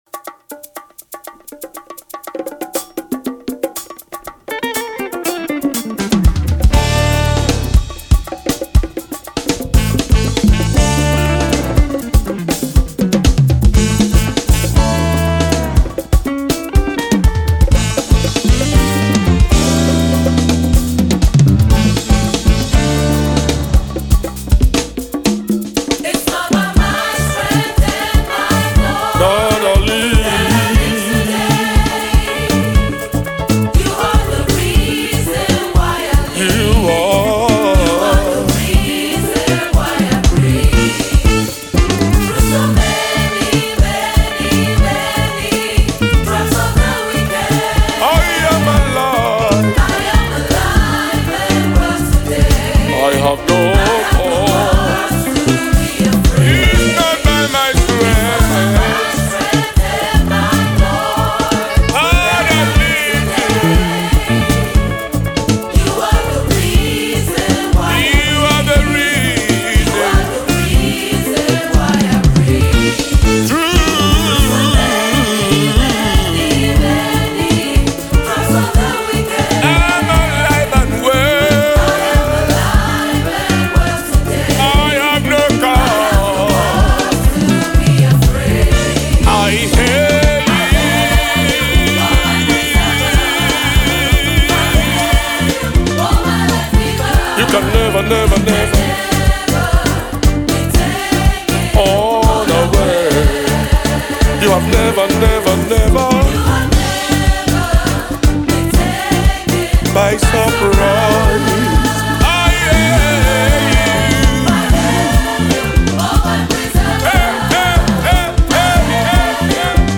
praise song